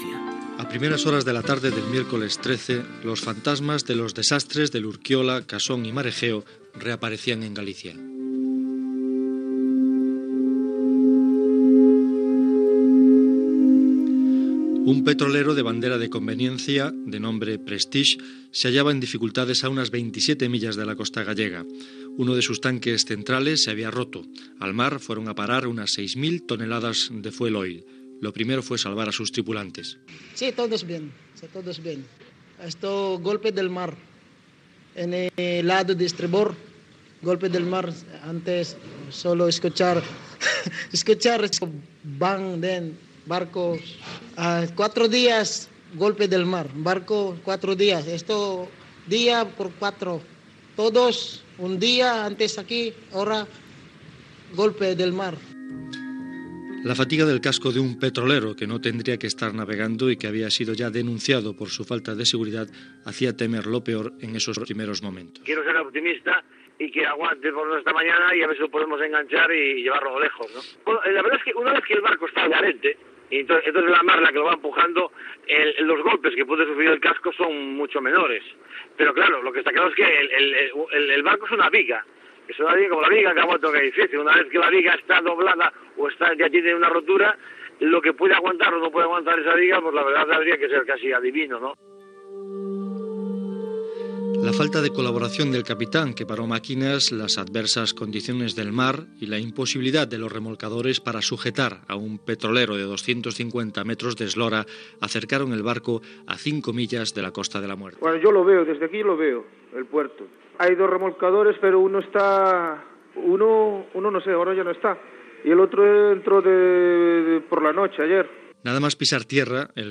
Reportatge una setmana després del naufragi del petrolier Prestige davant de Finisterre (Galícia). Cronologia dels fets, amb testimonis d'un dels mariners i de pescadors i mariscadors gallecs
Informatiu